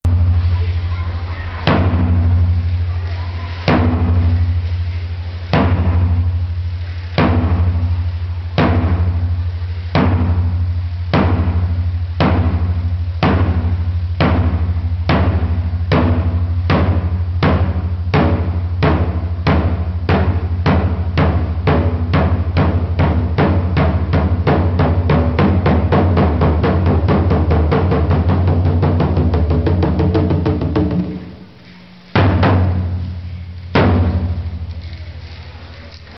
Tải Tiếng Trống Khai Giảng MP3
Tiếng trống khai giảng thường vang lên long trọng và dõng dạc trong buổi lễ khai trường.
mp3-tieng-trong-khai-truong-sach.mp3